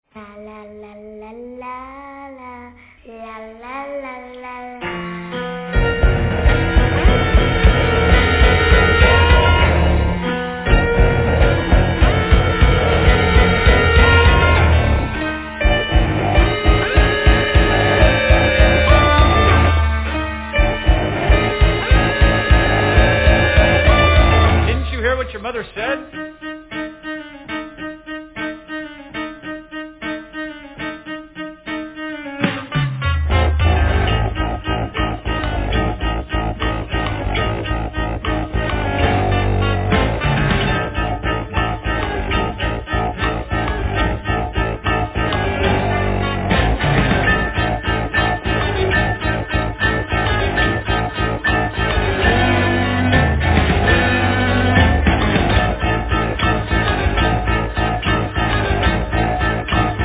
composition, vocals, direction, guitar
drums, percussion, vocals
piano, mellotron, vocals
bass
Cello